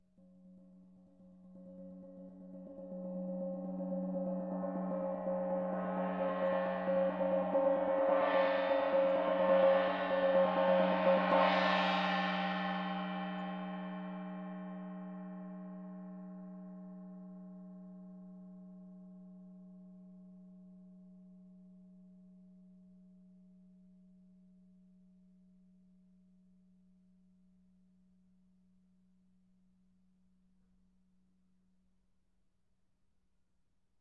中型锣鼓调至F，"锣鼓旋远3
描述：工作室录制中等大小的Gong调到F＃。使用ZOOM H4以96 kHz / 24位分辨率录制。
Tag: 远处 打击乐 金属